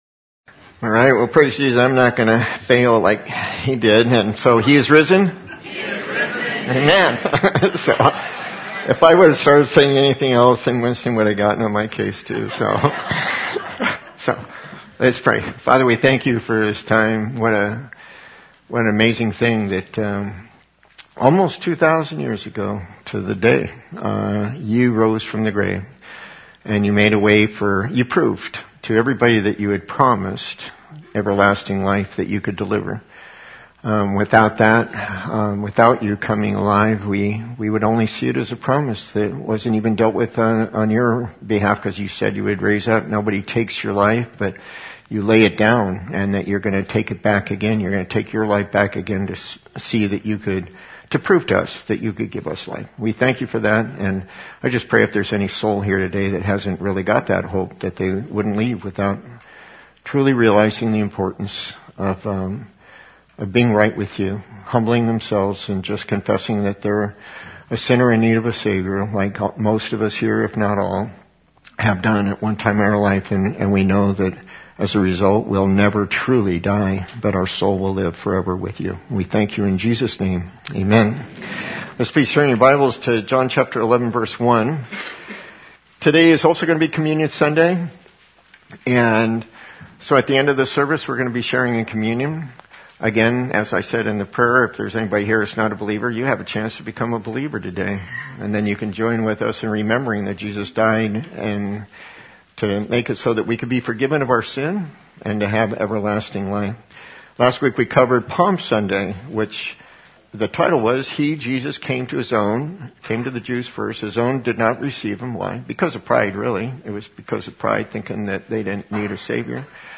Audio Link includes communion service at the end Loading...